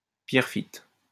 Pierrefitte (French pronunciation: [pjɛʁfit]